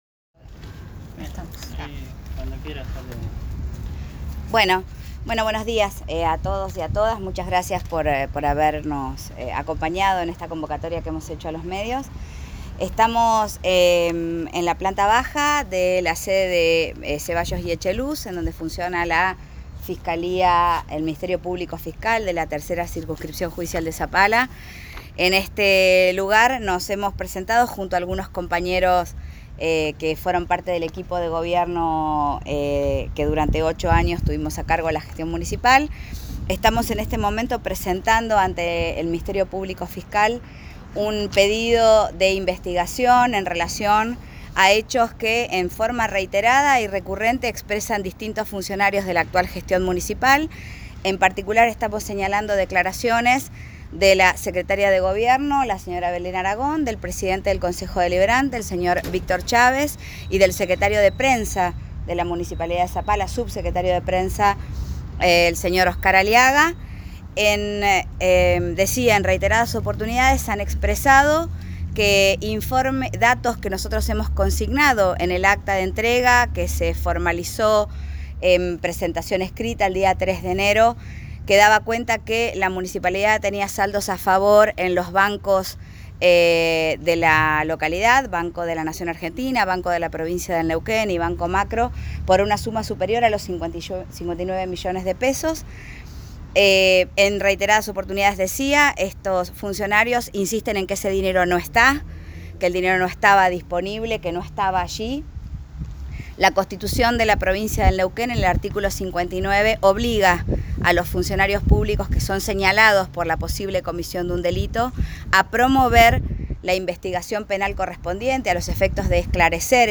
Conferencia-Soledad-Martínez.m4a